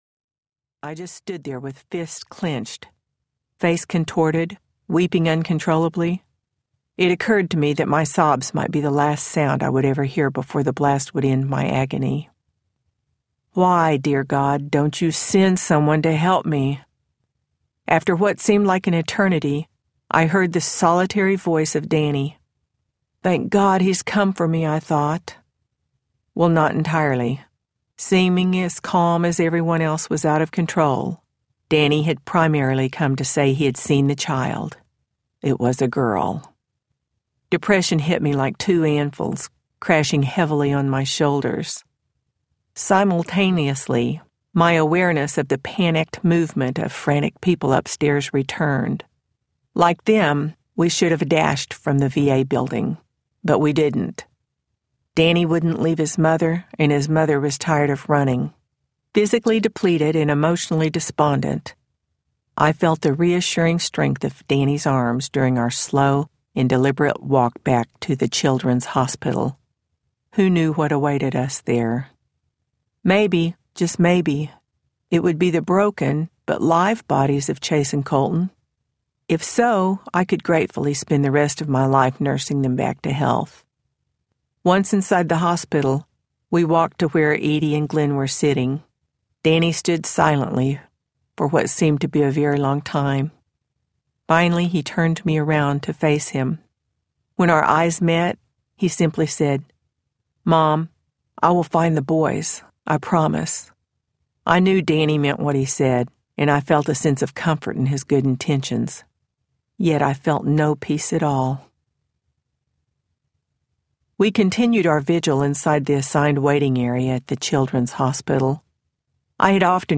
Now You See Me Audiobook